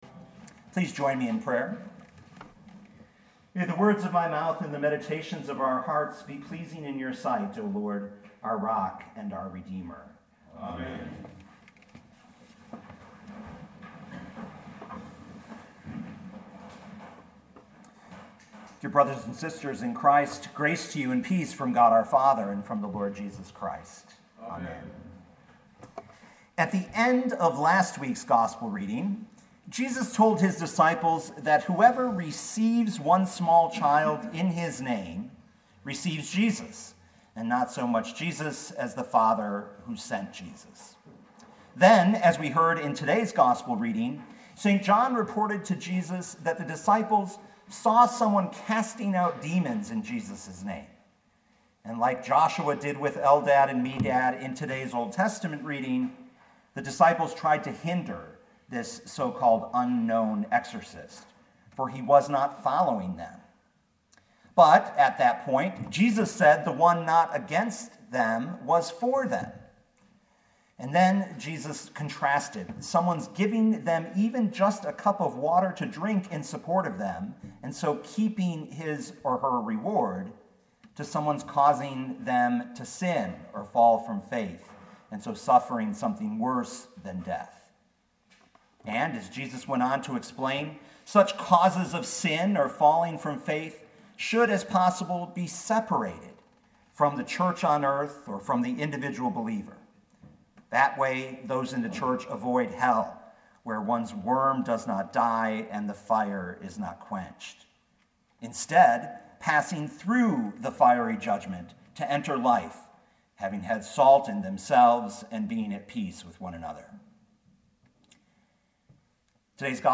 19th Sunday after Pentecost
2018 Mark 9:38-50 Listen to the sermon with the player below